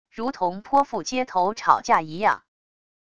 如同泼妇街头吵架一样wav音频